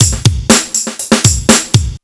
RUFFBEAT 121 2.wav